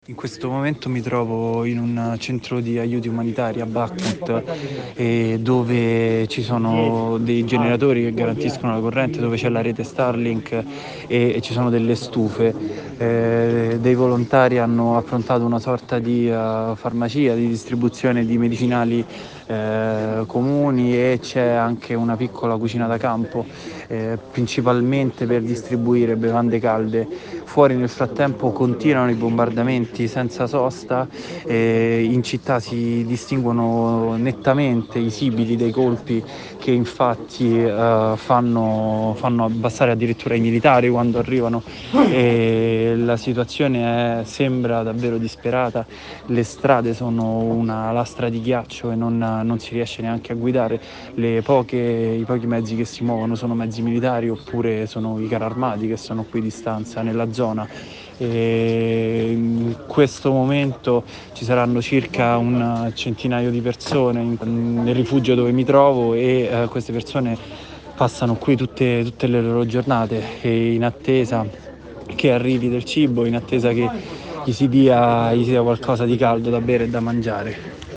Noi proprio a Bakhmut abbiamo raggiunto il nostro collaboratore